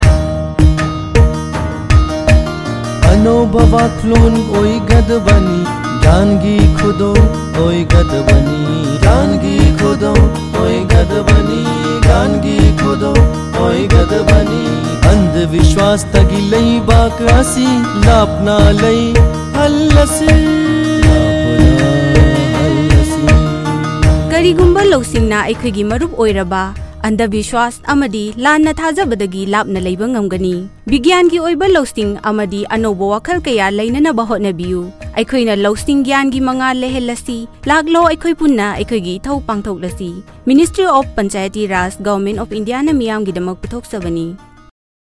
142 Fundamental Duty 8th Fundamental Duty Develop scientific temper Radio Jingle Manipuri